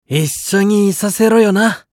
男性
熱血系ボイス～恋愛系ボイス～